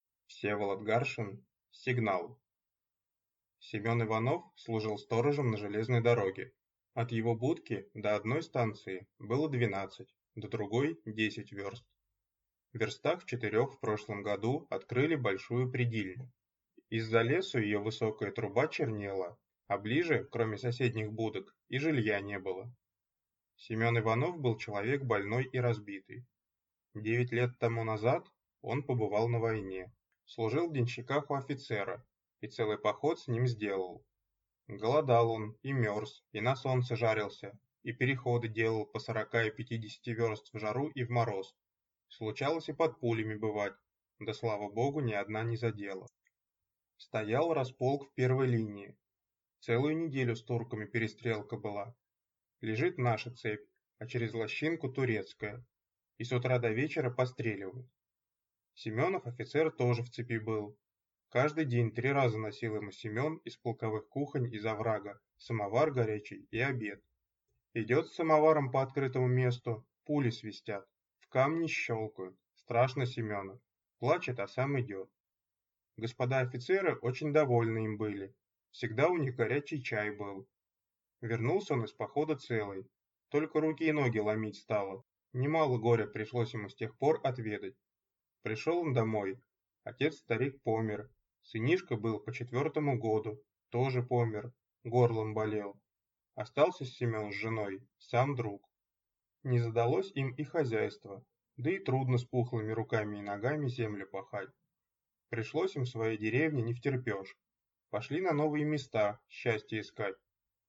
Аудиокнига Сигнал | Библиотека аудиокниг